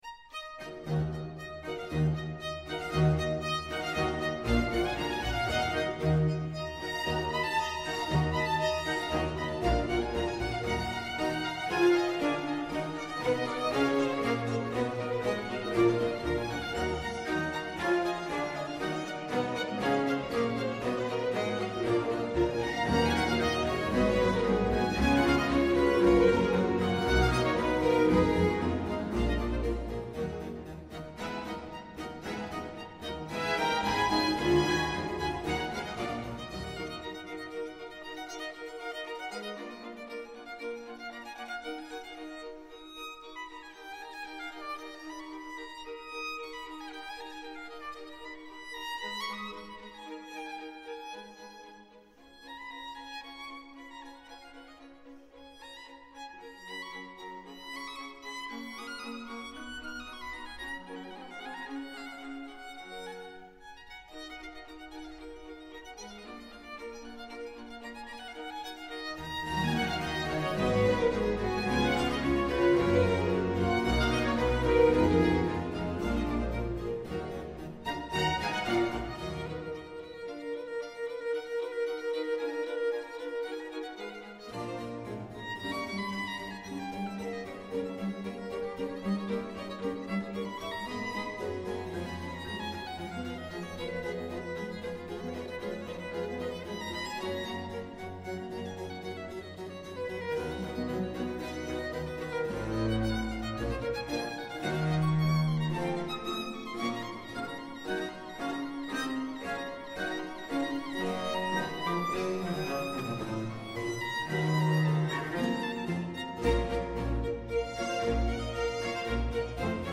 Σήμερα τηλεφωνική σύνδεση